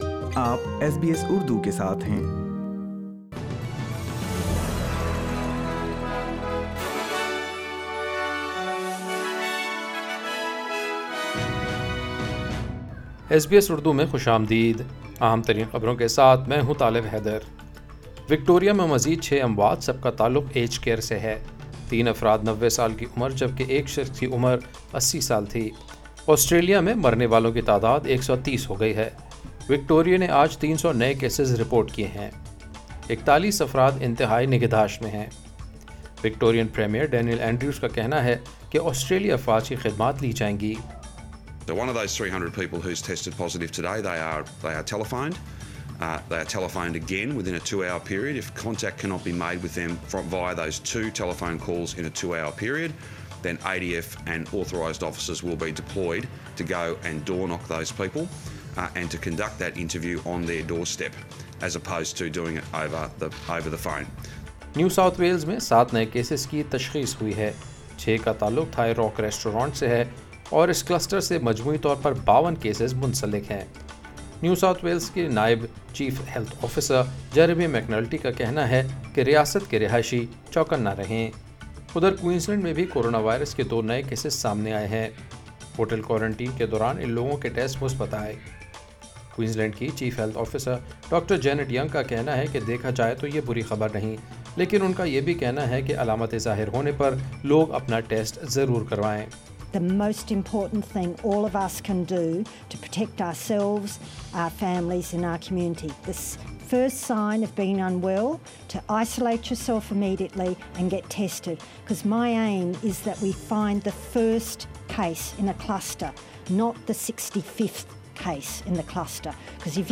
Army personnel to conduct door-knocking in Victoria as state records 300 cases. Six more people have died in Victoria from the coronavirus- all of them connected to aged care. Catch the latest news in Urdu.